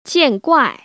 [jiàn//guài] 지앤꽈이